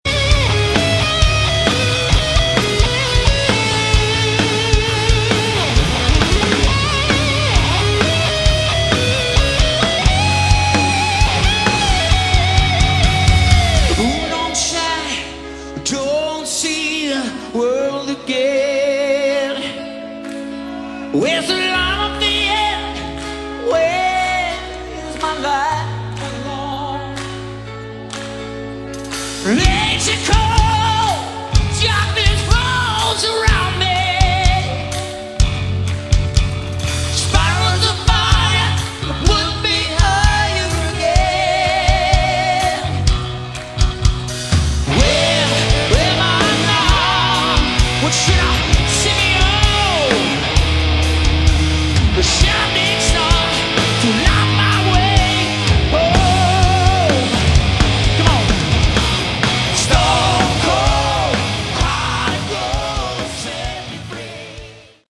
Category: Hard Rock / Melodic Rock
keyboards, vocals
guitar, backing vocals
bass
drums